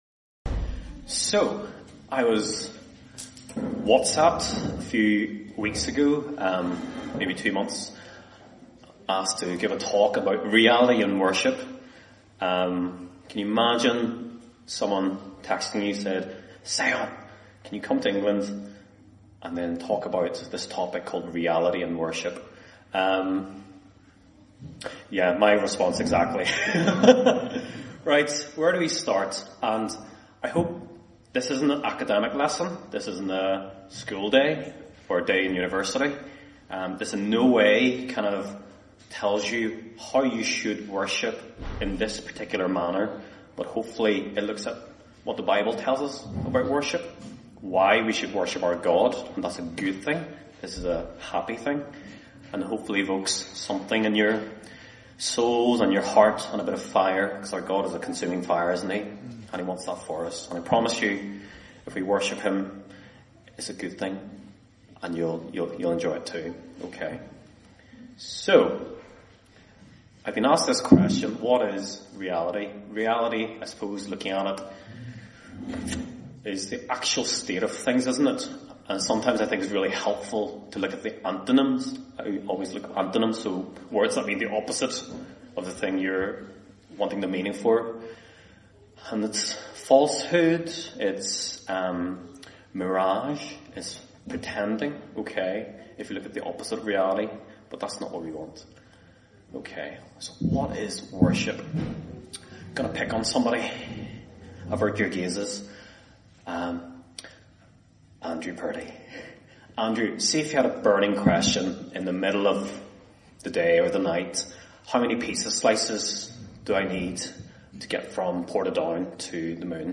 This year at Refresh 2024 we looked at the subject of Reality and what it means to be a real Christian. In this talk, we will look at the reality of true Worship. The scriptures says God is a Spirit and He must be worshiped in spirit and truth. Obstacles to true worship can so easlily come to hinder our worship with God.